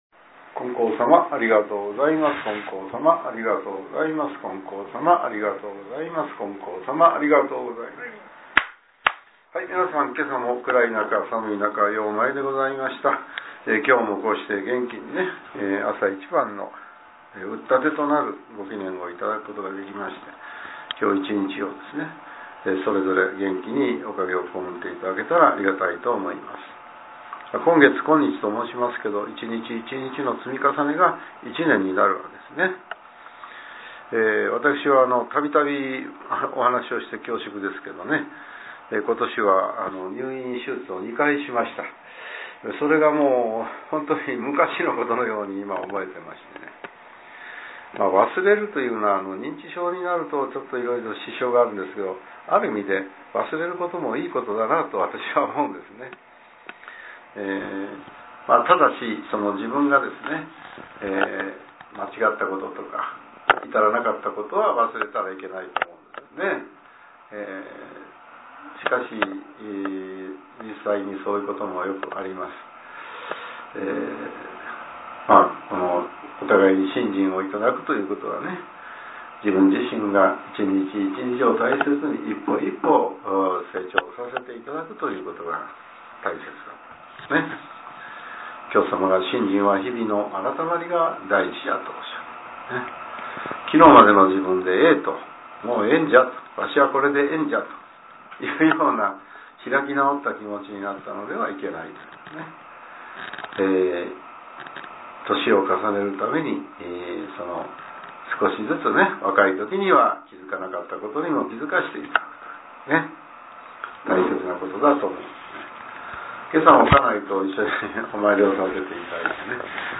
令和６年１２月１６日（朝）のお話が、音声ブログとして更新されています。